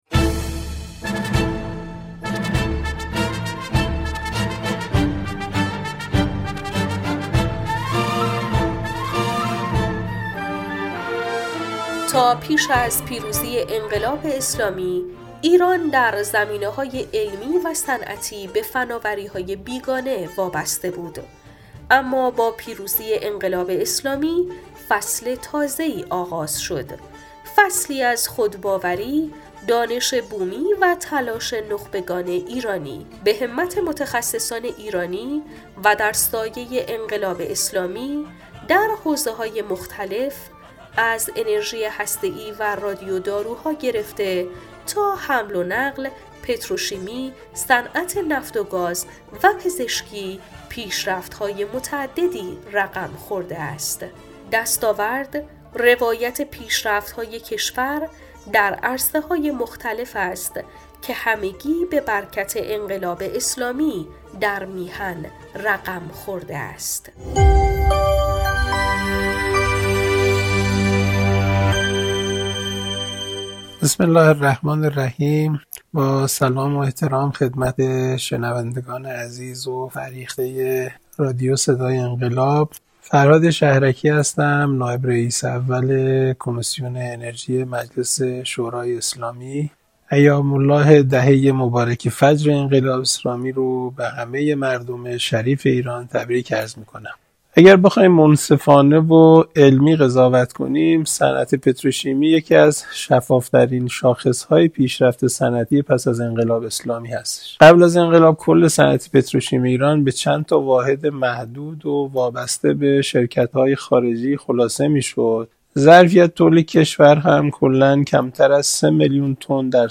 کارشناس: فرهاد شهرکی، نائب رئیس اول کمیسیون انرژی مجلس شورای اسلامی